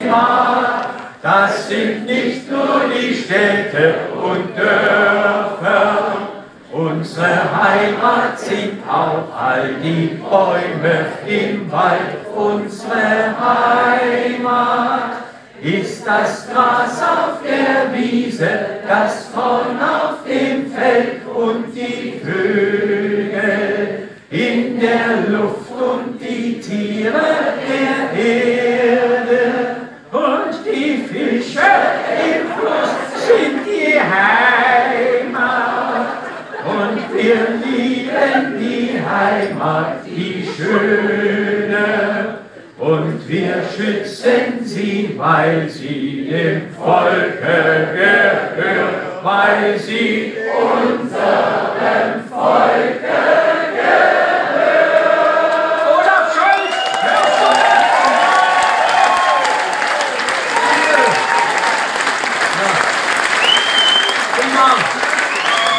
"Kleine weiße Friedenstaube" - gemeinsam gesungen.